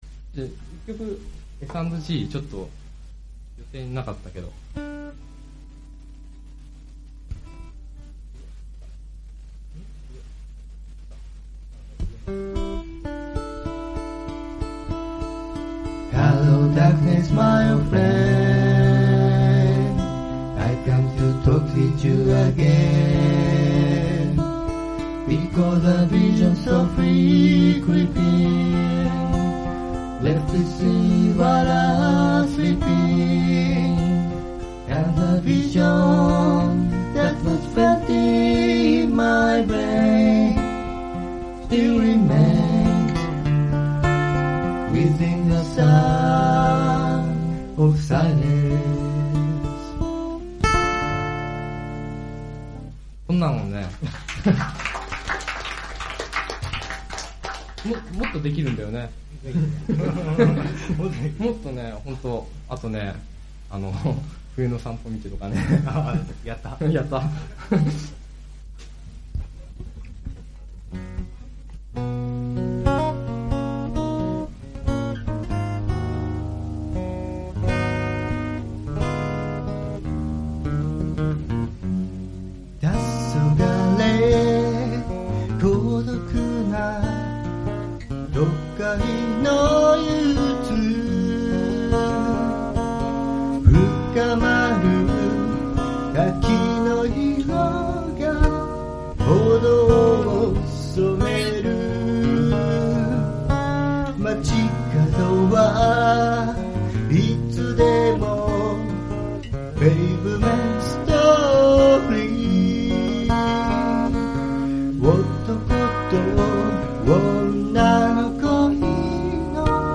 Vocal,A.guitar
Vocal